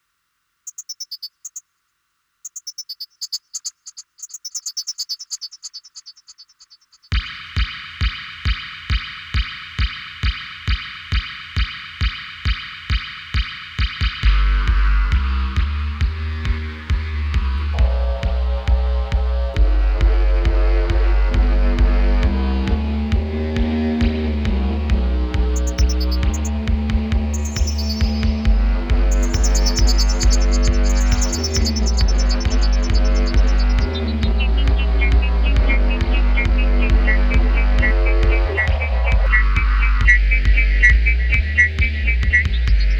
the B-side delivers the corresponding dub mix
this release brings the 90s-style riddim to life.